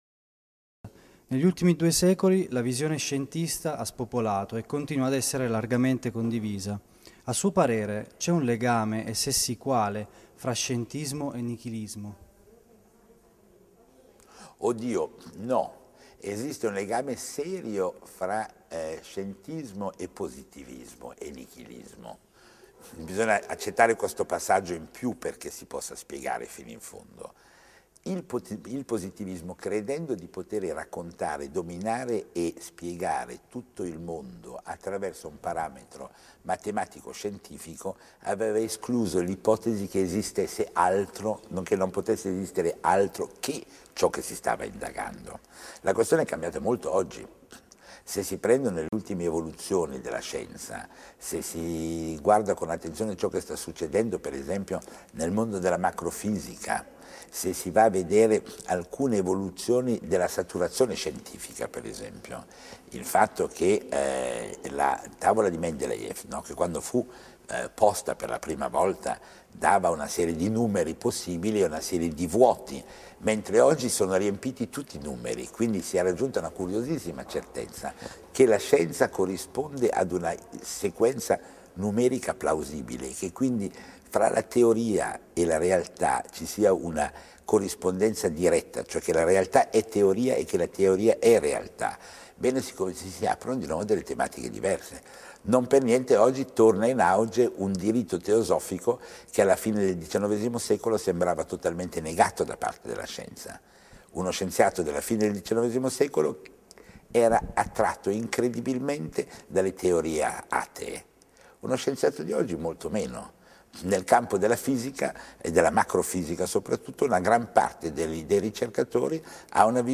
Intervista a Philippe Daverio, critico d'Arte e conduttore televisivo
Podcast delle vacances de l'esprit Intervista a Philippe Daverio, critico d'Arte e conduttore televisivo In occasione del Festival della Scienza di Genova abbiamo intervistato il famoso critico d'Arte Philippe Daverio Clicca qui per ascoltare l'intervista Durata minuti 24:40